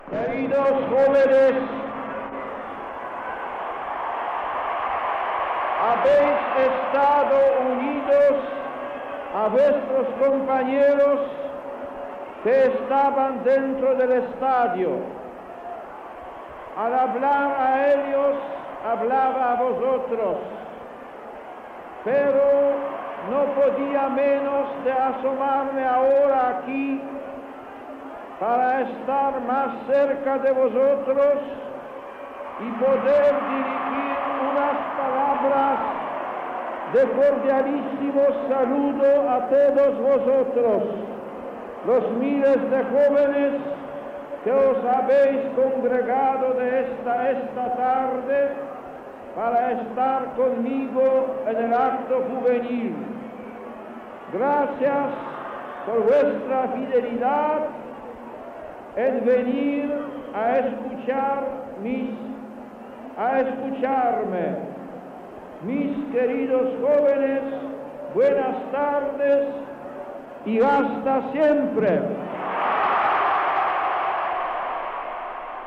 Homilía del sant pare Joan Pau II en l'acte amb els joves a l 'Estadio Santiago Bernabéu de Madrid en la seva primera visita a Espanya.
Paraules del sant pare Joan Pau II als joves que estaven fora de l 'Estadio Santiago Bernabéu de Madrid
Informatiu